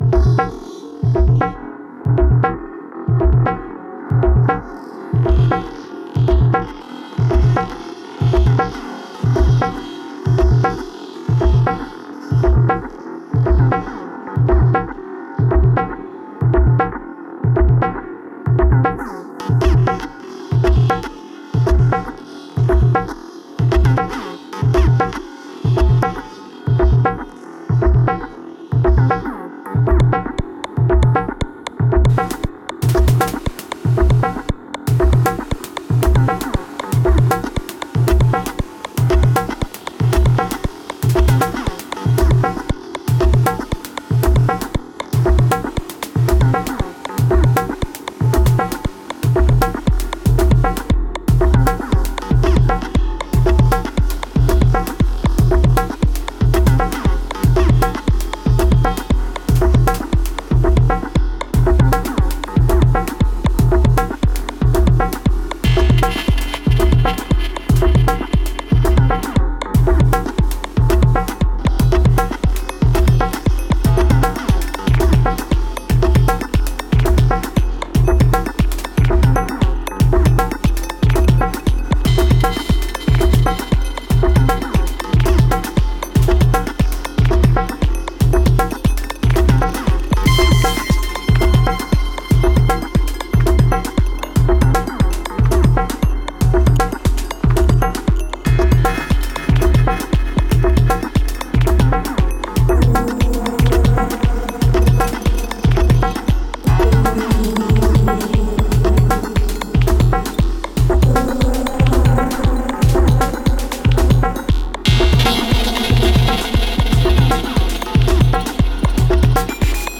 Unfiltered Audio - Battalion (soft drum synth)
Little jam with Battalion today (plus a couple of loops). Some of the levels are out but loving the sounds you can get with this thing, Battalion comes in at about 40s: